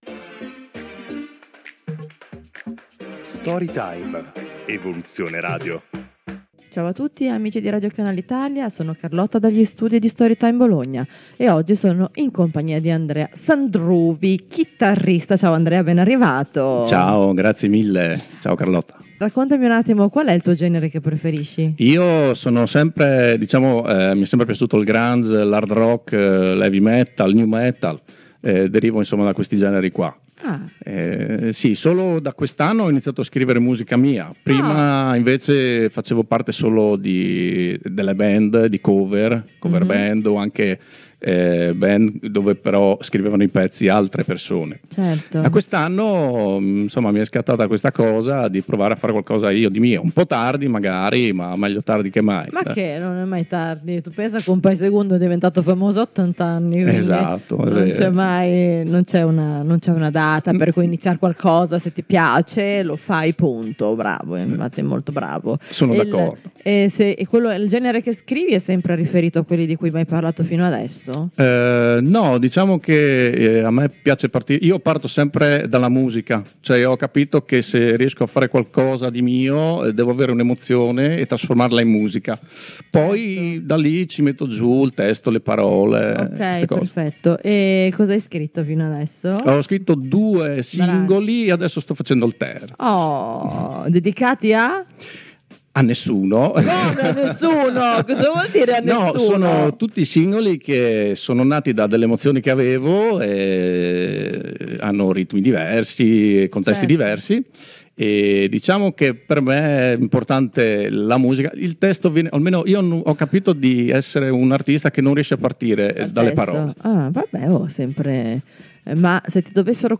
chitarrista